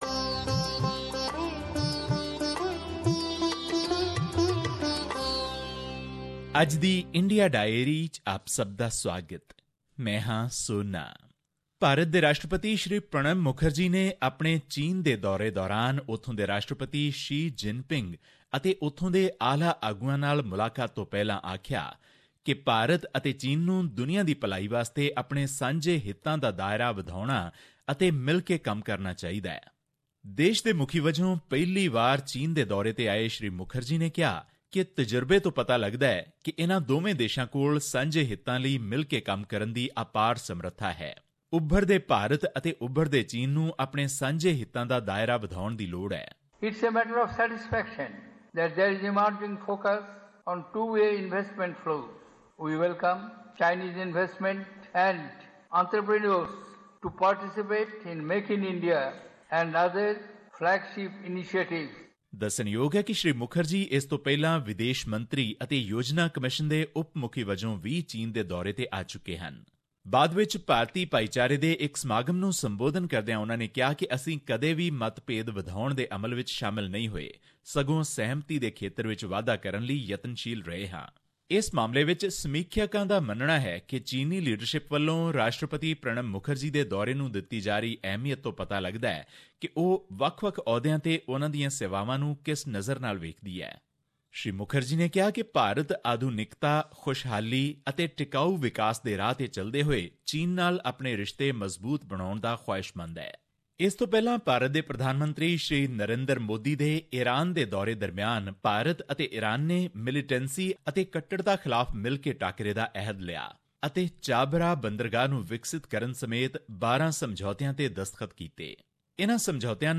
Here's the podcast in case you missed hearing it on the radio.